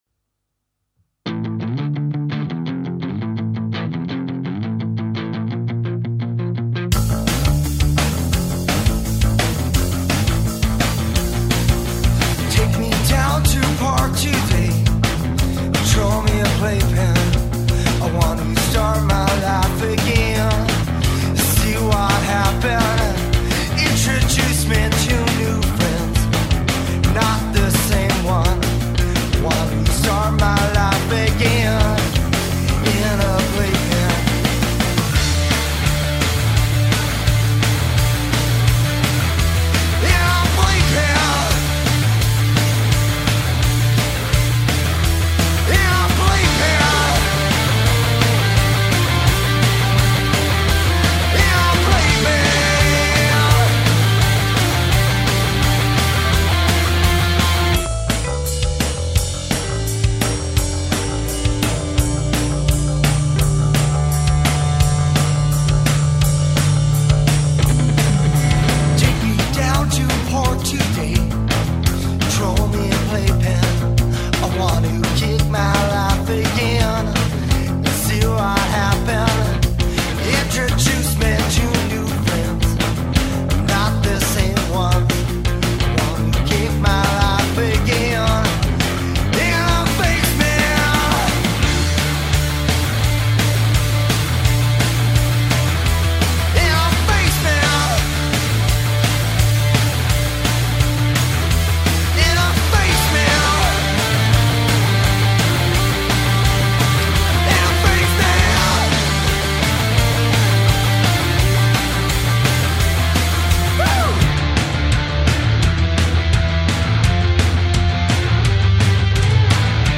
punk/grunge band